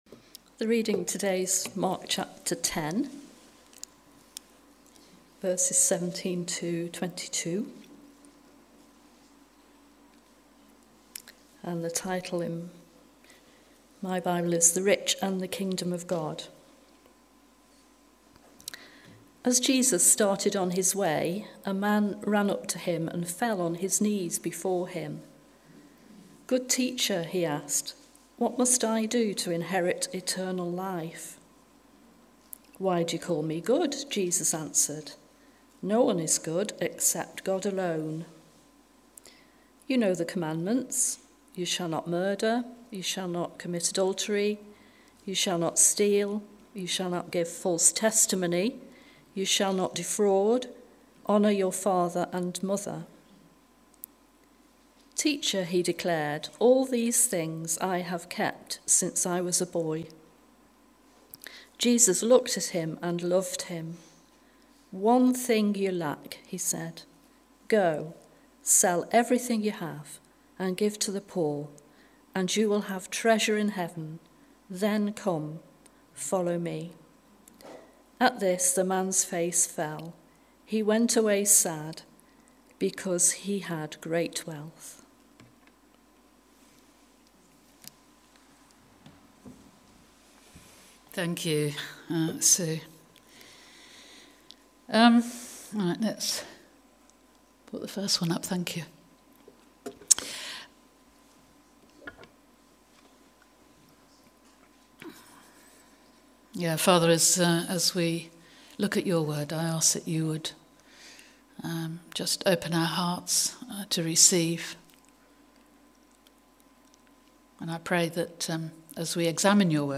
Apologies - the last part of the talk was not recorded due to technical issues.